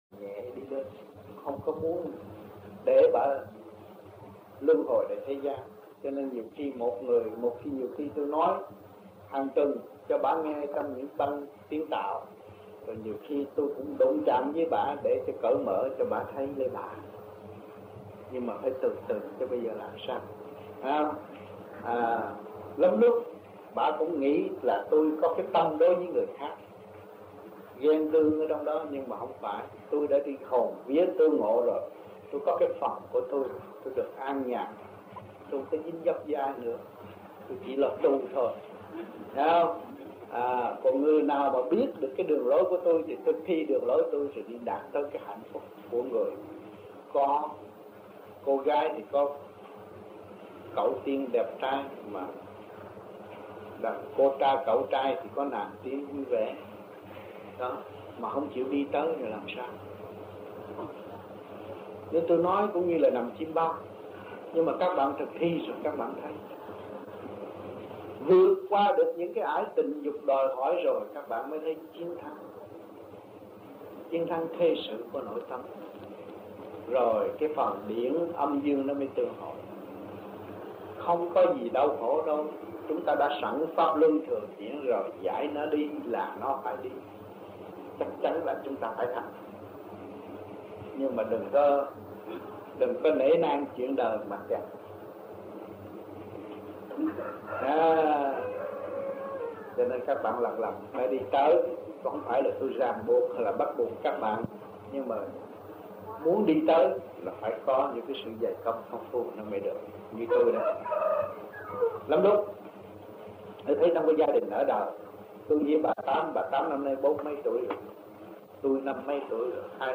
1975-03-30 - SÀI GÒN - THUYẾT PHÁP 3